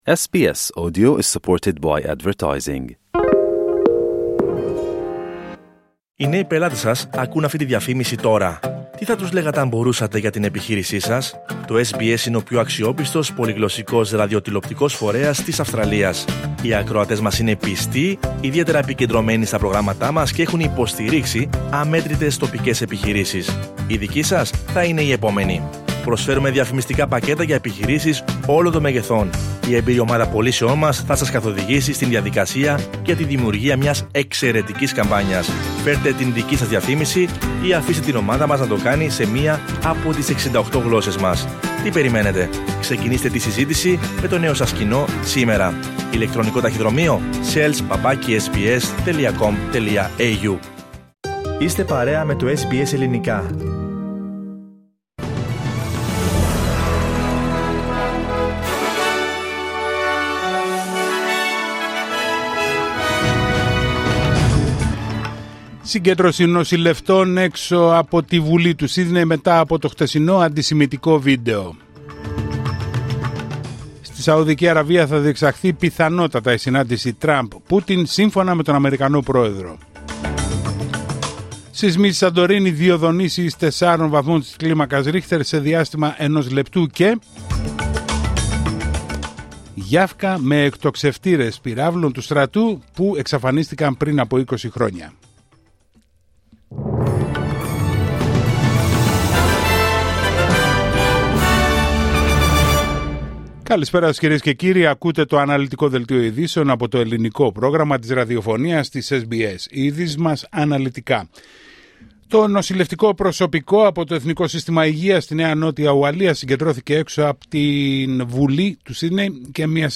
Δελτίο ειδήσεων Πέμπτη 13 Φεβρουαρίου 2025